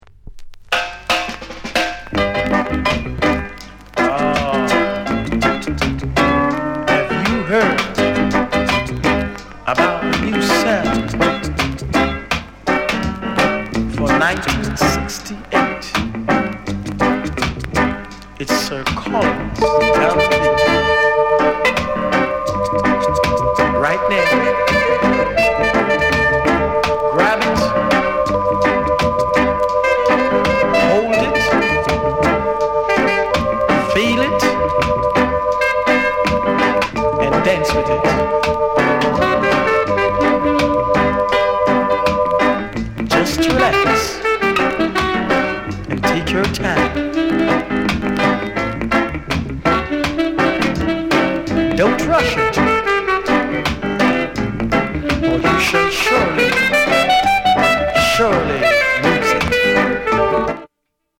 RARE ROCKSTEADY